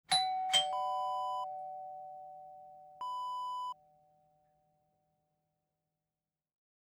Doorbell Ding-Dong Wav Sound Effect #4
Description: Doorbell ding-dong
A beep sound is embedded in the audio preview file but it is not present in the high resolution downloadable wav file.
doorbell-preview-4.mp3